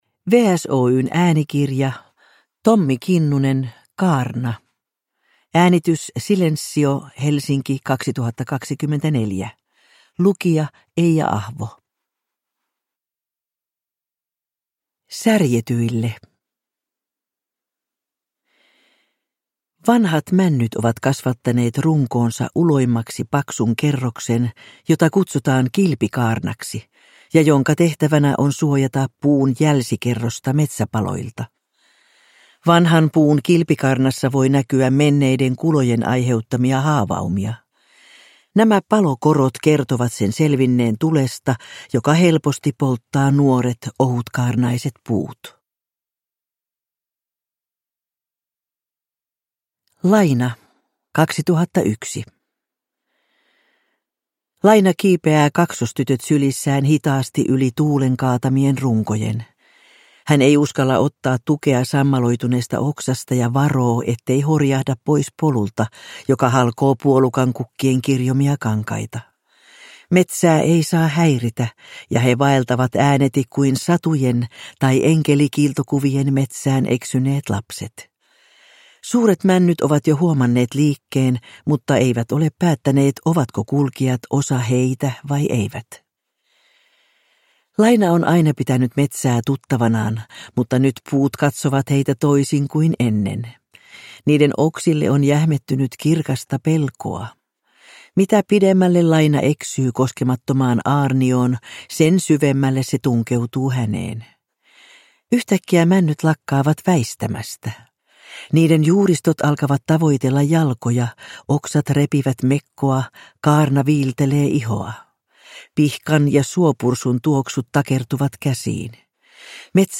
Kaarna (ljudbok) av Tommi Kinnunen